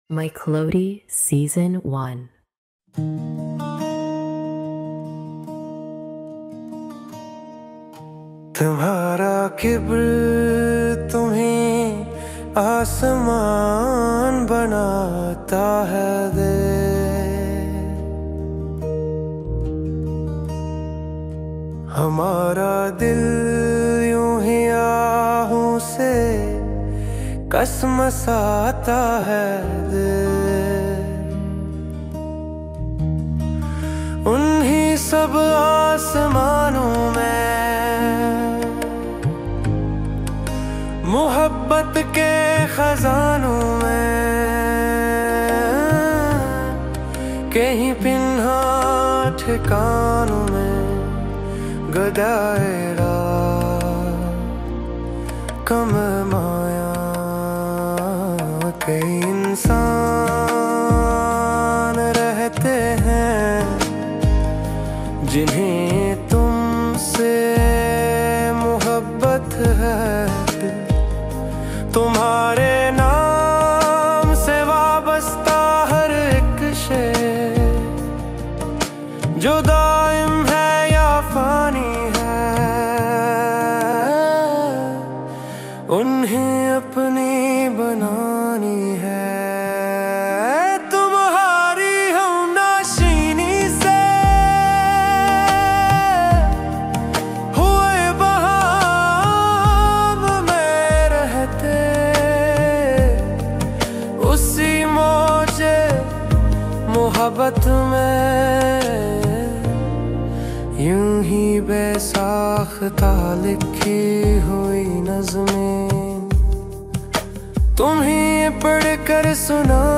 •  Lyrics born from the heart, melodies crafted with AI.